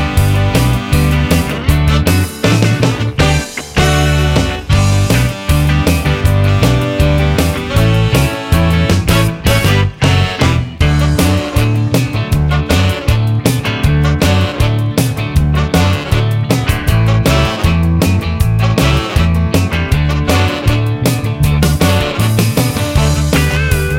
No Backing Vocals Rock 'n' Roll 2:53 Buy £1.50